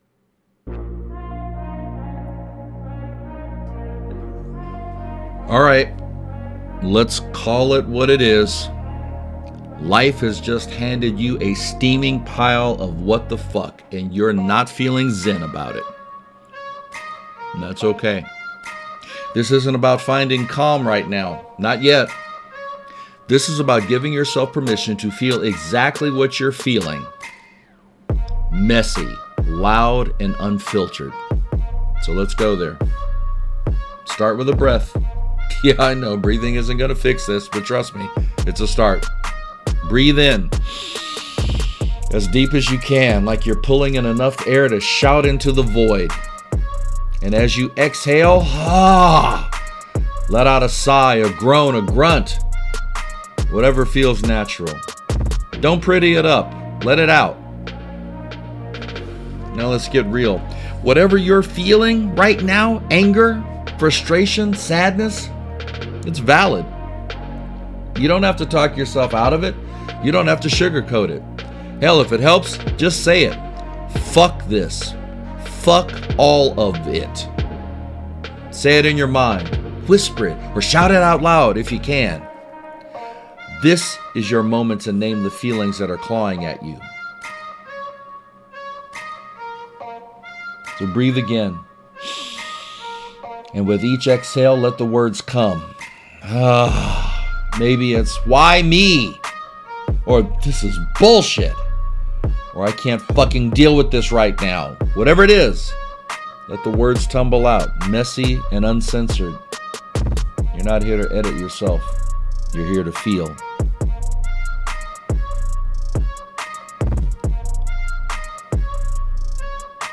Guided Meditation for Stress and #&%^$#
When stress feels unrelenting, this lighthearted meditation brings laughter, relief, and emotional release. (Warning: Adult language ahead—it’s real and raw!)